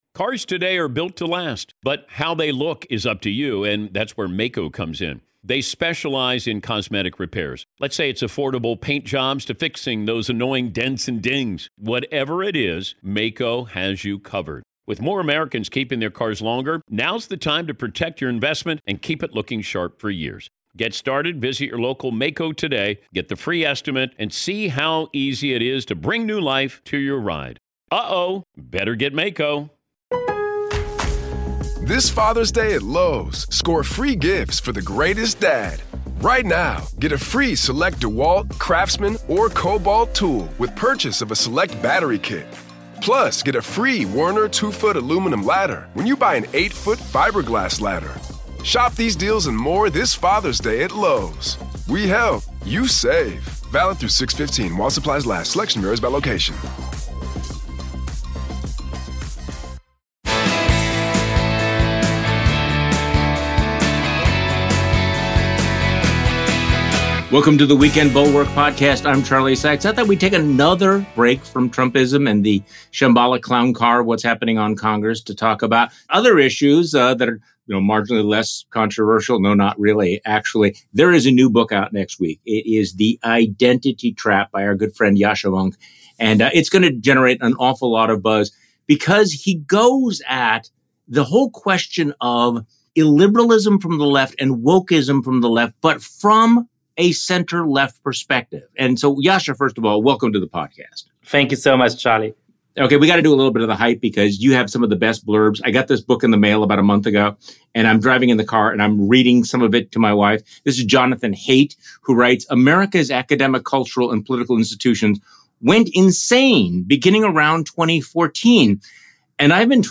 While the immediate crisis may be from the right, the left is presenting its own threats—which only helps make far-right populism stronger. Political scientist and democracy hipster Yashca Mounk discusses his new book with Charlie Sykes on the weekend pod.